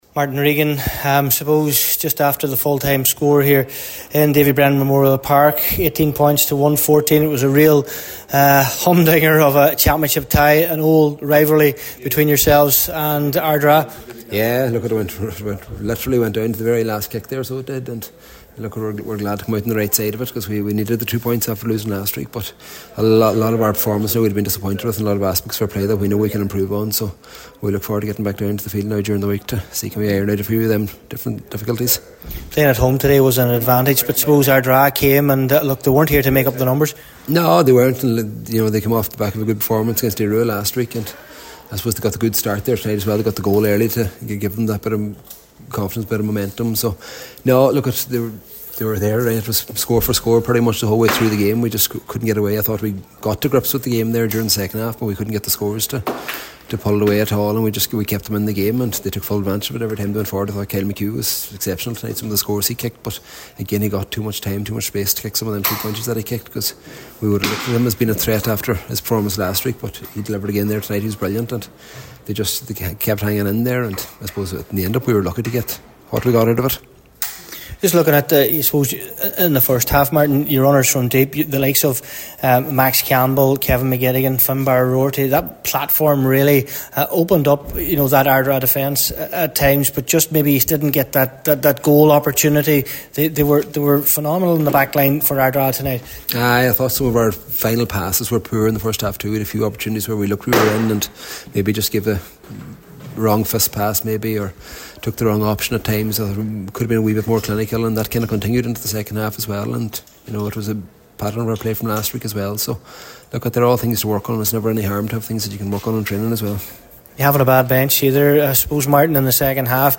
at full time in what was an exciting contest…